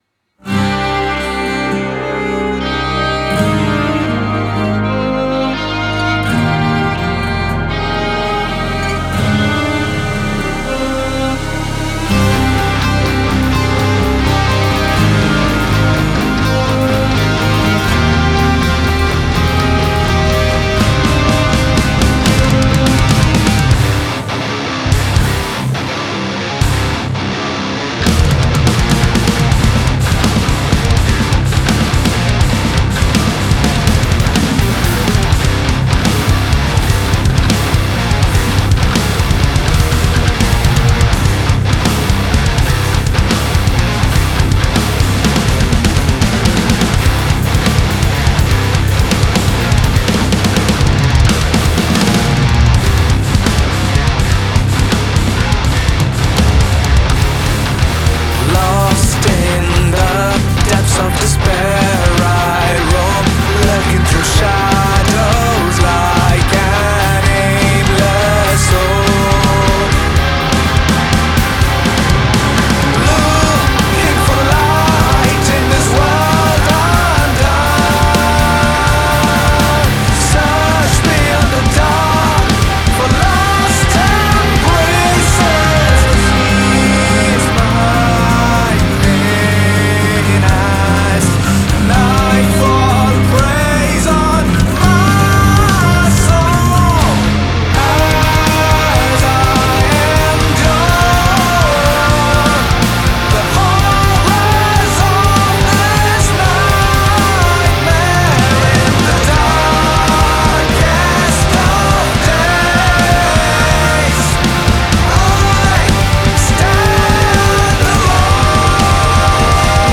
Melodic Metal Metalcore